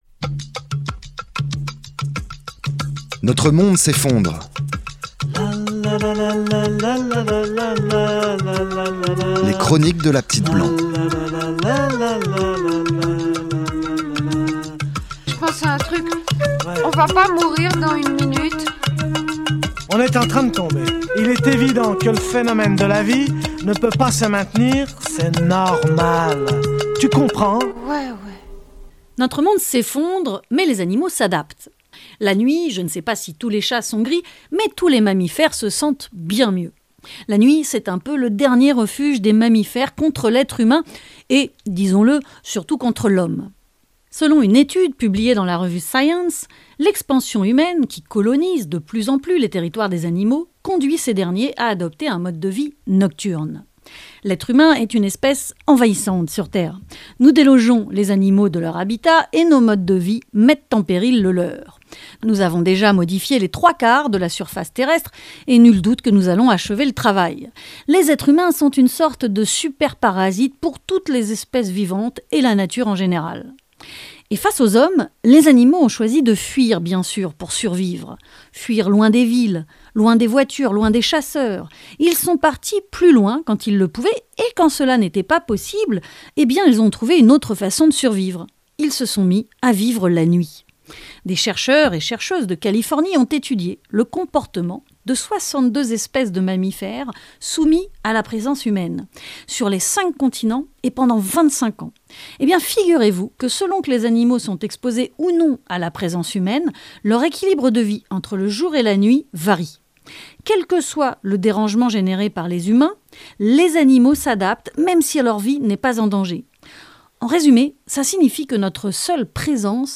Emission radiophonique en direct tous les mercredis de 19h à 20H30